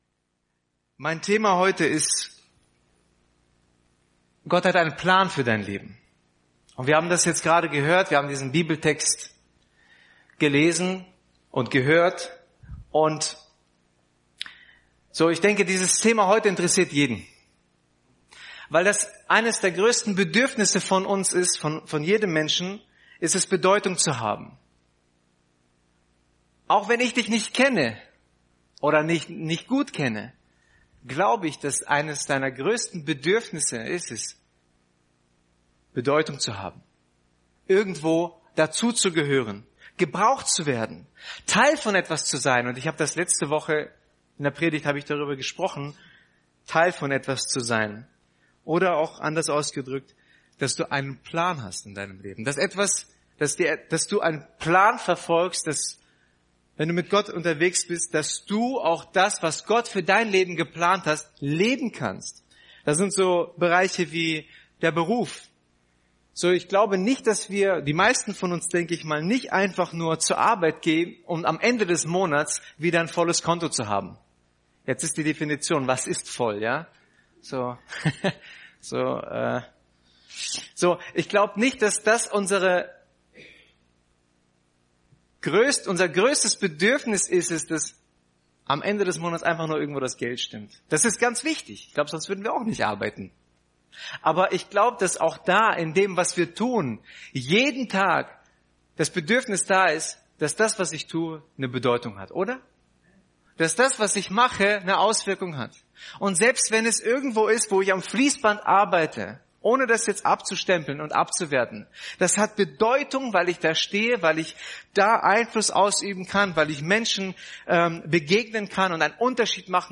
Teil 5 der Predigtreihe zum Epheserbrief über die Verse 1-13 in Kapitel 3 des Epheserbriefes.